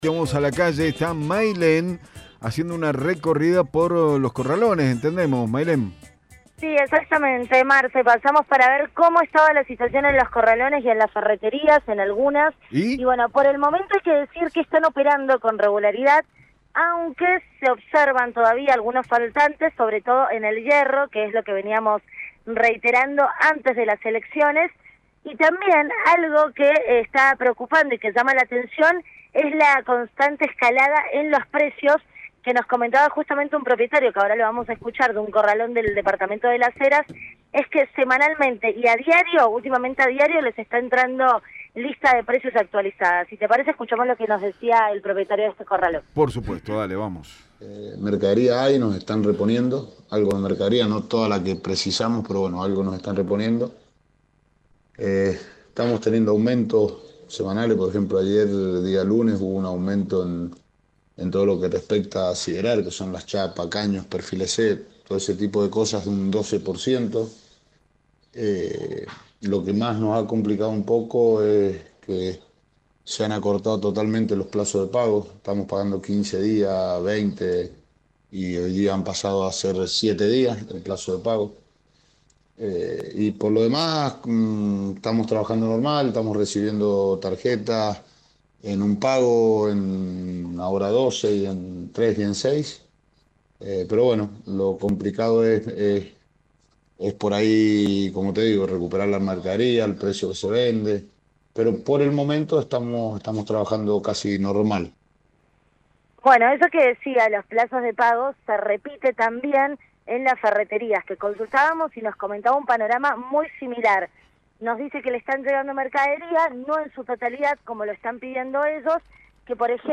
Móvil de LVDiez – Panorama en Corralones y Ferreterías